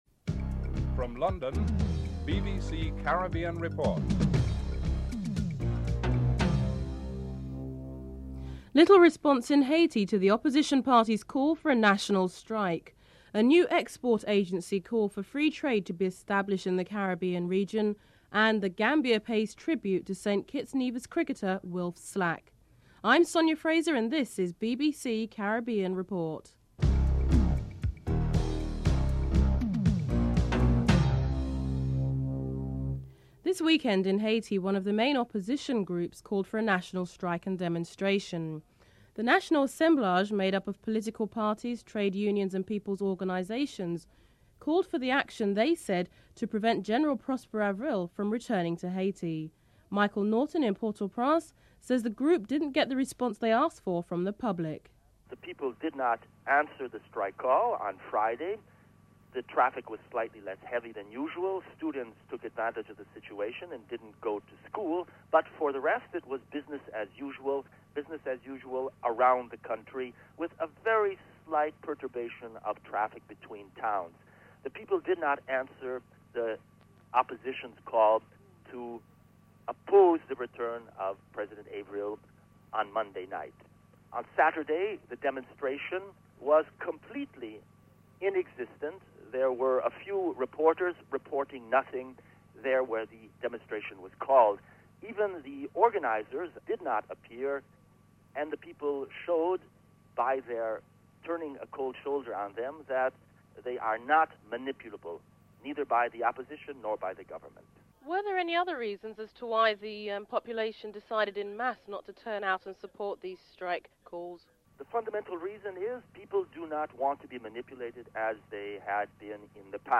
1. Headlines (00:00-00:32)
7. Sporting segment.